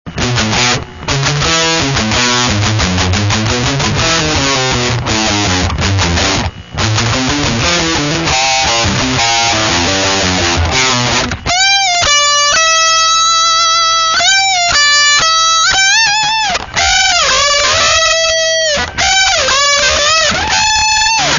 lead guitar.